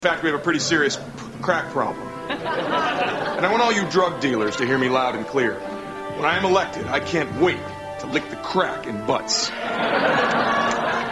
Category: Comedians   Right: Personal
Tags: Pat Finger is Running in Butts Pat Finger Running in Butts Pat Finger funny skit snl skit